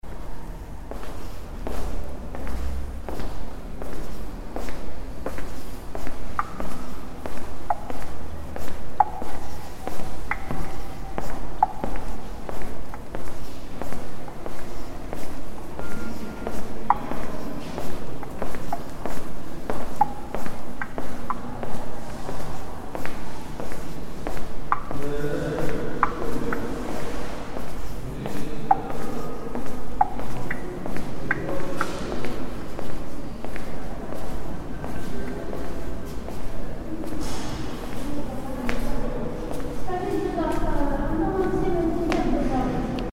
Ternopil train station, Ukraine